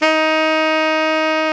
Index of /90_sSampleCDs/Roland L-CD702/VOL-2/SAX_Tenor V-sw/SAX_Tenor _ 2way
SAX TENORM0G.wav